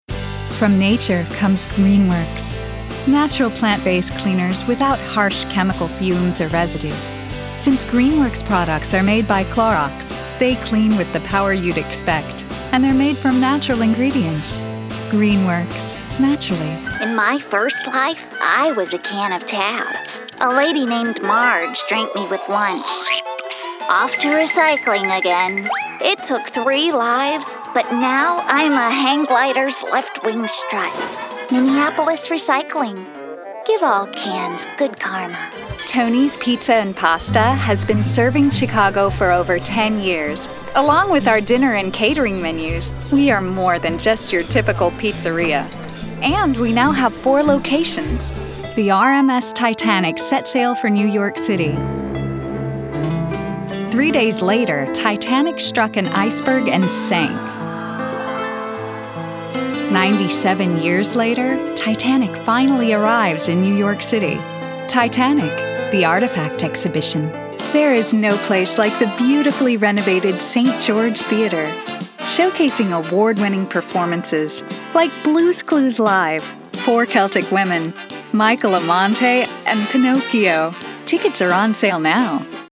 Natural, fresh style. Deliveries ranging from professional and straightforward, to casual conversation, to hip and quirky characters.
Commercial